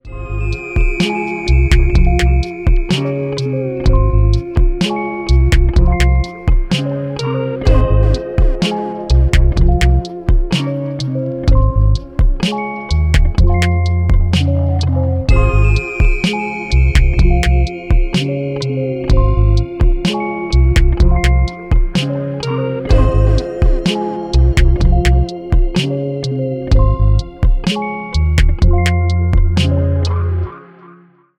Рэп и Хип Хоп
без слов